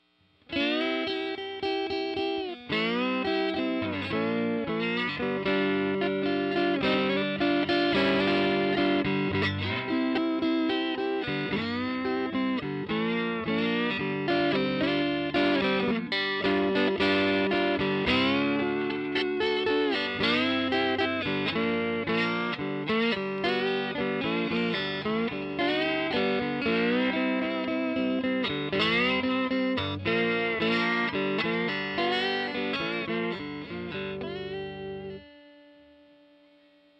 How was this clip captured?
Recorded using SM57 and Tone Tubby 212 bomb.